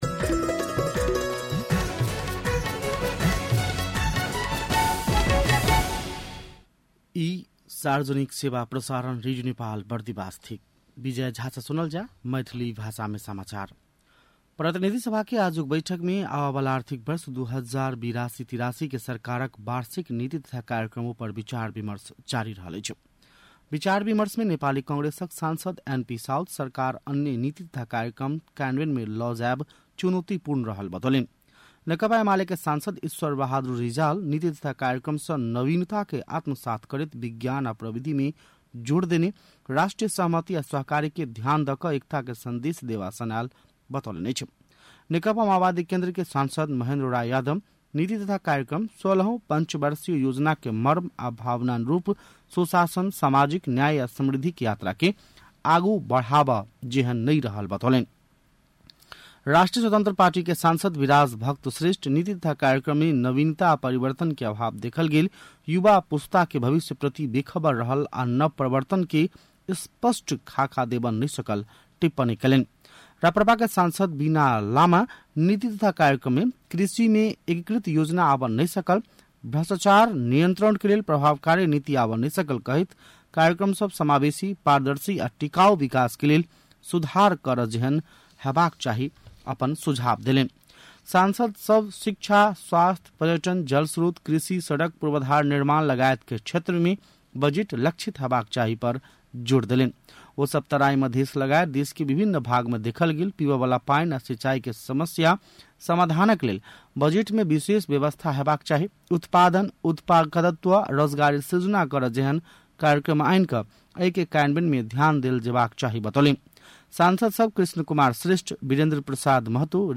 मैथिली भाषामा समाचार : २४ वैशाख , २०८२
Maithili-NEWS-01-24.mp3